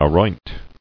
[a·roint]